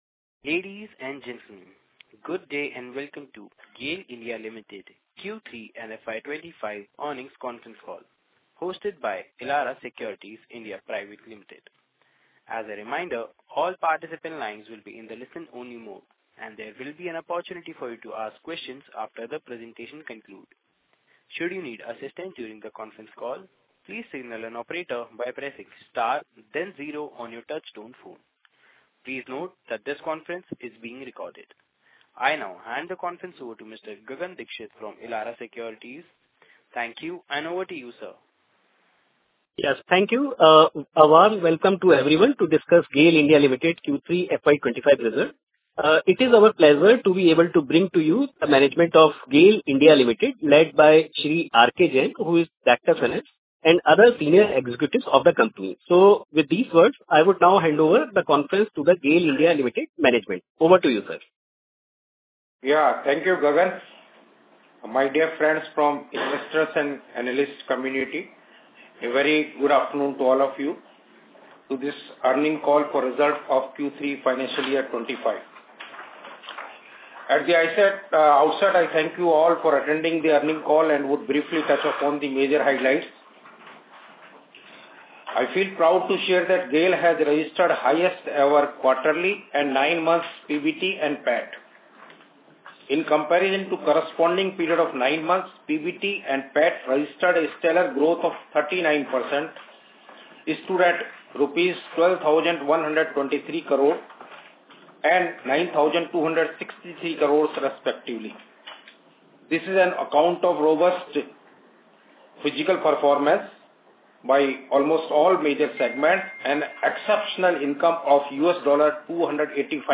Audio Recording GAIL Earnings Call Q3 FY25.mp3